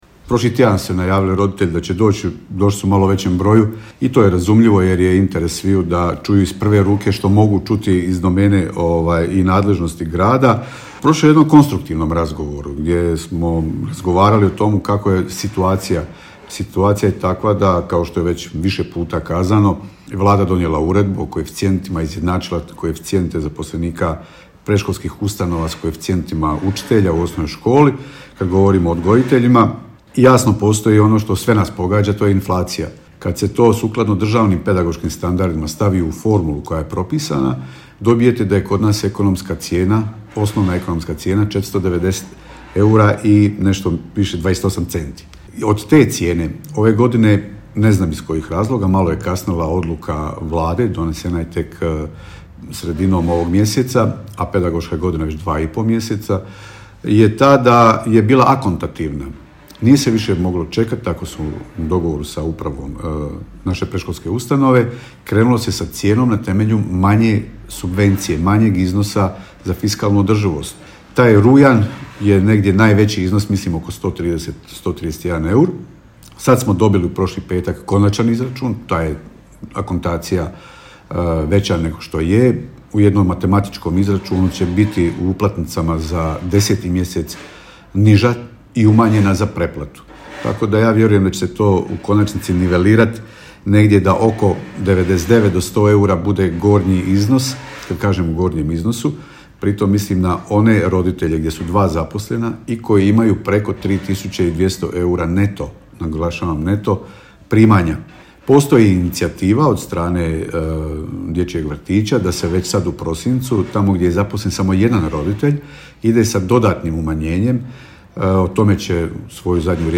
Nakon sastanka gradonačelnik Josip Begonja je kazao::